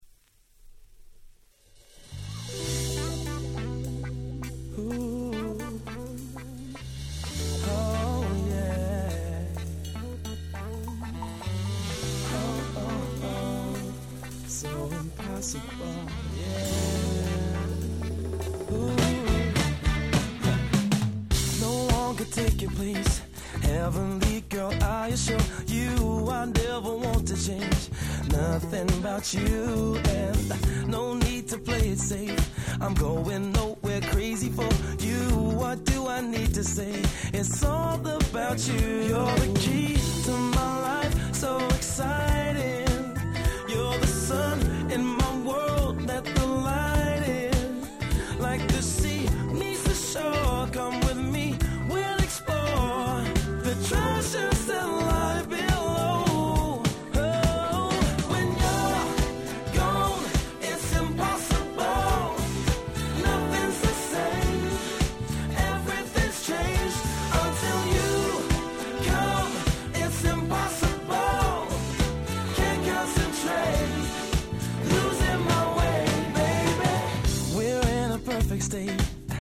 05' Super Nice UK R&B/Neo Soul !!